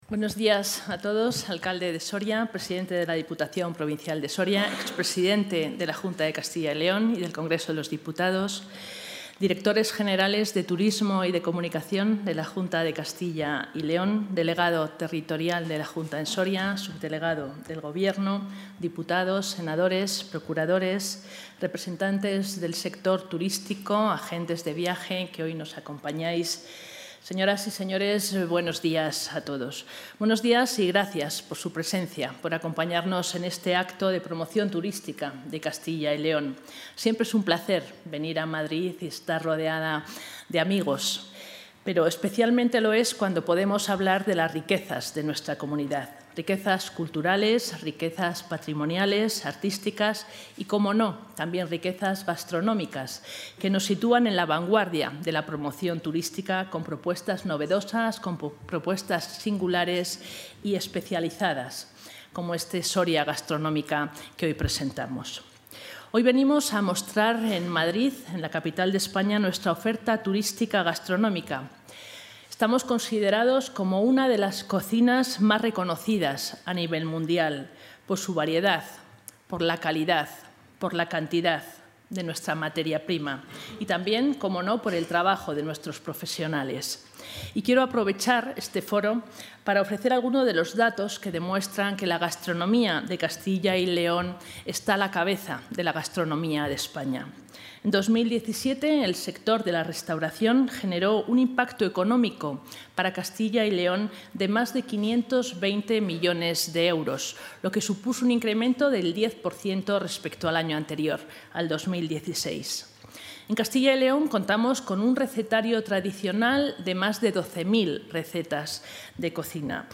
La consejera de Cultura y Turismo, María Josefa García Cirac, ha presentado hoy en Madrid el VI Congreso Internacional de...
Consejera de Cultura y Turismo.